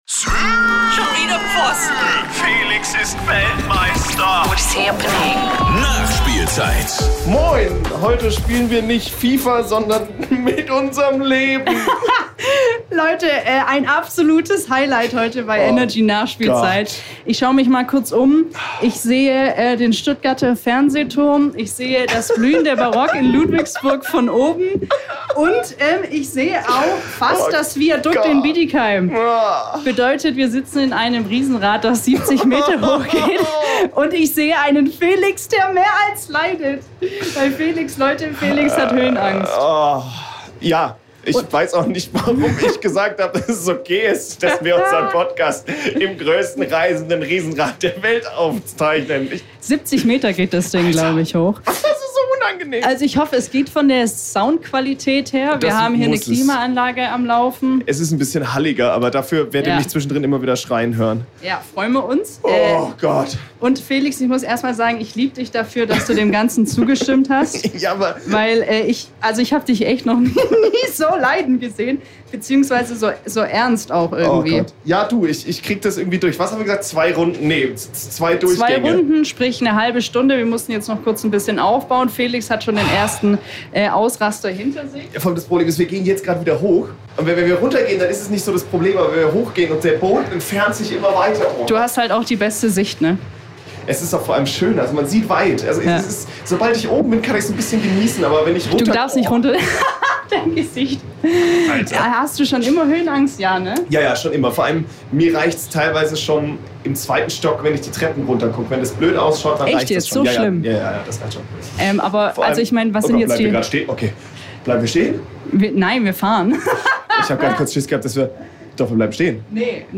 Die Themen, neben viel Geschrei: Geisterhäuser, Mailbox-Ansagen und erfundene Gerüchte!